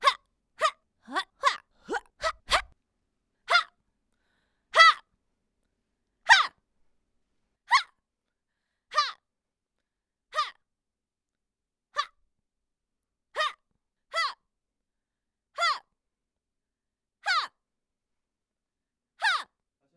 哈.wav 0:00.00 0:19.98 哈.wav WAV · 1.7 MB · 單聲道 (1ch) 下载文件 本站所有音效均采用 CC0 授权 ，可免费用于商业与个人项目，无需署名。
人声采集素材/女激励/哈.wav